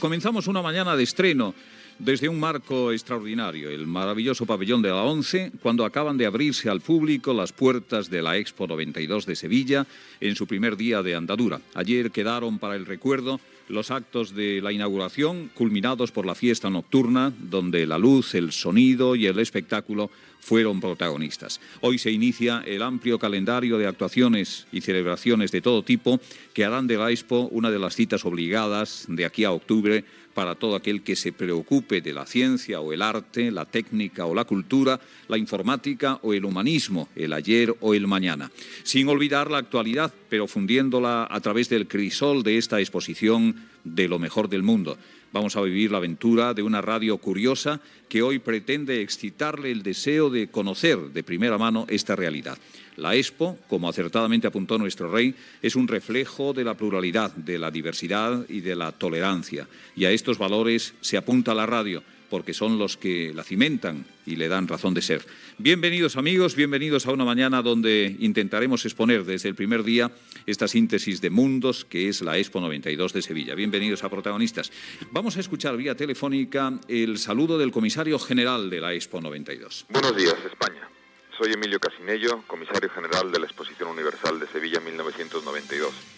Inici del programa des del pavelló de l'ONCE a l'Expo 92 de Sevilla. Salutació d'Emilio Cassinello, Comisari general de l'Expo.
Info-entreteniment